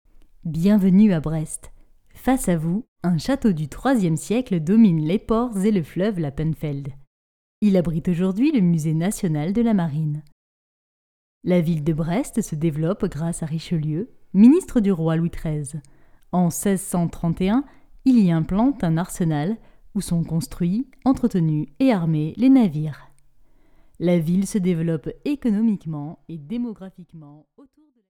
I’m a native French voice-over artist and producer with years of experience delivering high-quality voice work.
Gear: I use Ableton Live 11, a MOTU UltraLite interface, and a Blue Baby Bottle microphone for professional-grade sound.
Sprechprobe: Industrie (Muttersprache):